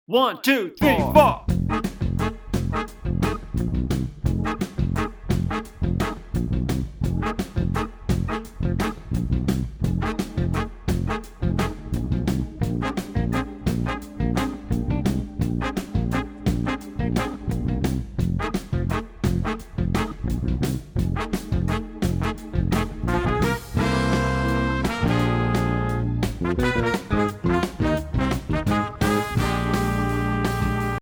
Voicing: Tenor Sax